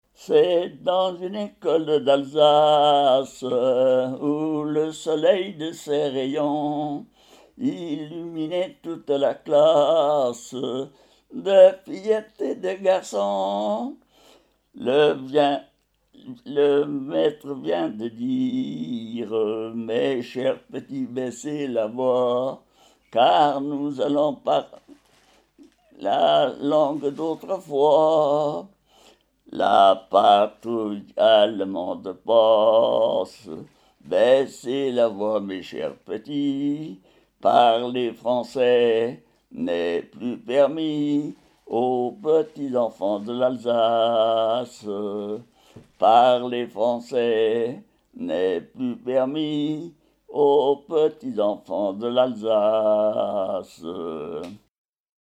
Genre strophique
Enquête Arexcpo en Vendée-Pays Sud-Vendée
Pièce musicale inédite